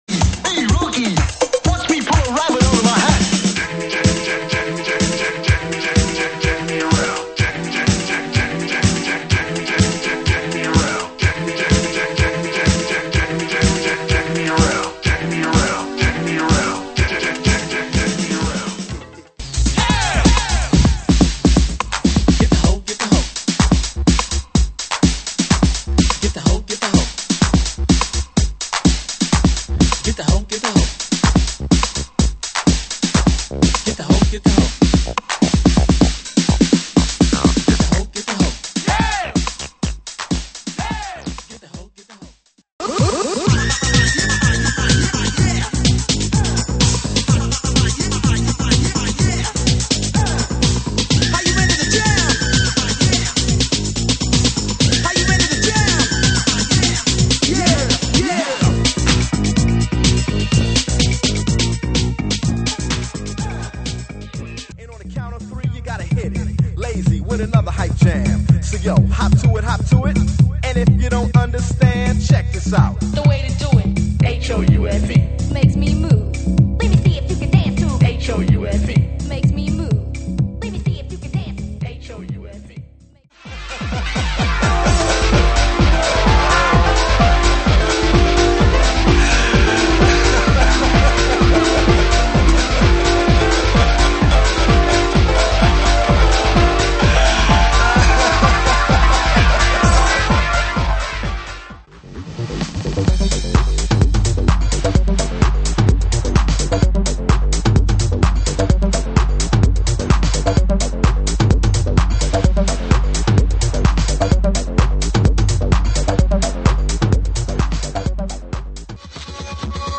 Mix CD / Mix Tape
今回は彼の最も得意とするシカゴスタイルでのミックス。
今作はゲットーミュージックを愛するがあまり、それらをミニマル的な手法でじっくりと聴かすという事に焦点を置いている。
トラックがぶつかるレゾナンスも意識して、シカゴのトラックが持つ凹凸の可能性を愛でるかの如くミックス。